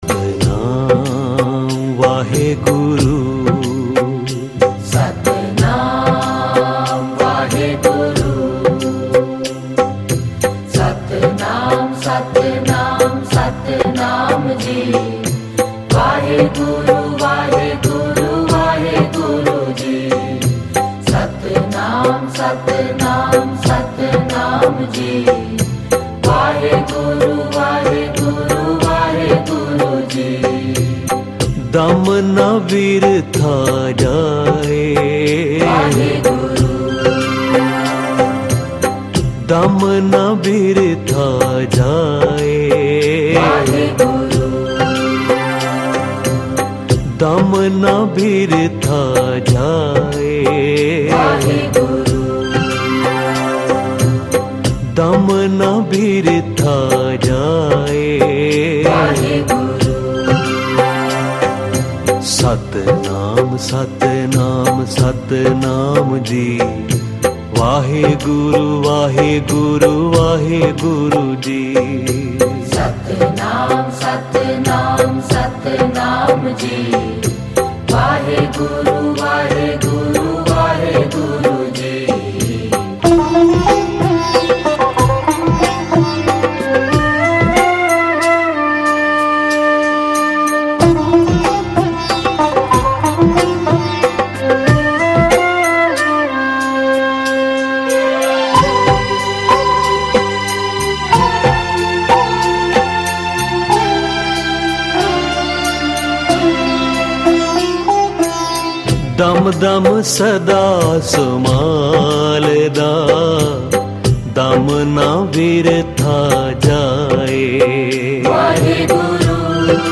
Kirtan with katha